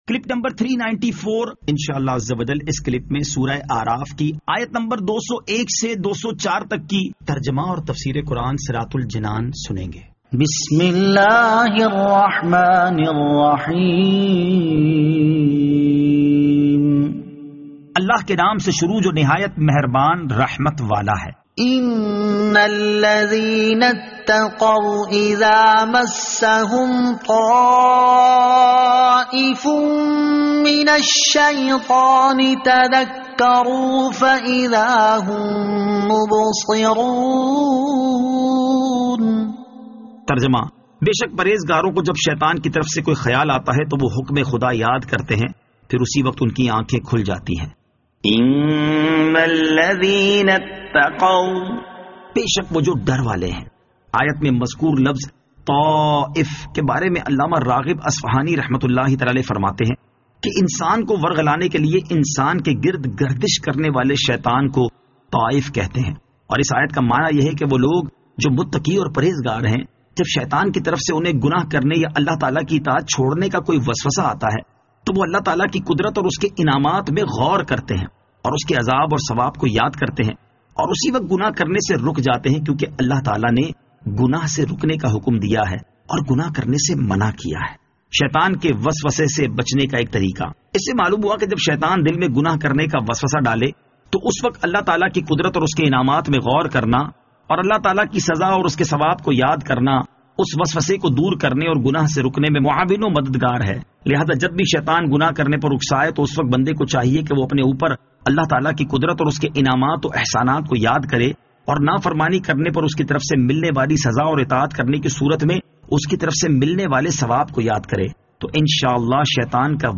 Surah Al-A'raf Ayat 201 To 204 Tilawat , Tarjama , Tafseer